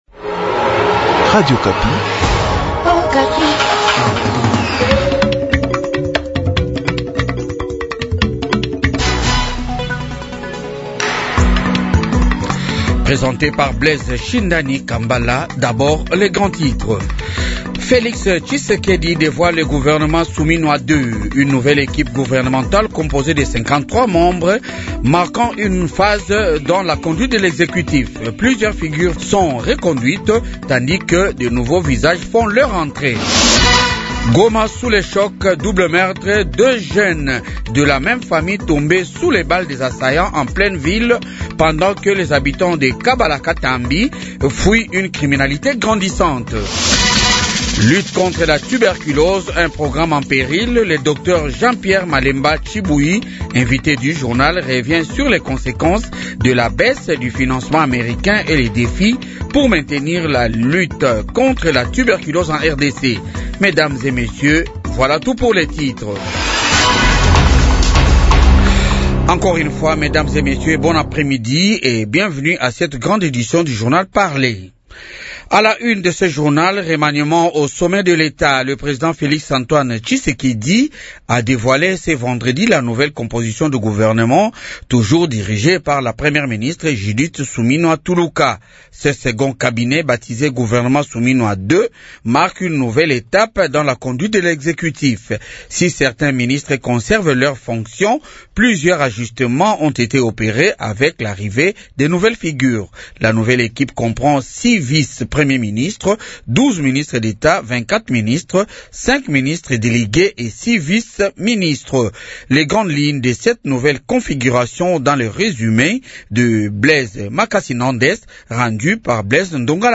Journal de 15h
-KIN: Vox pop nouveau gouvernement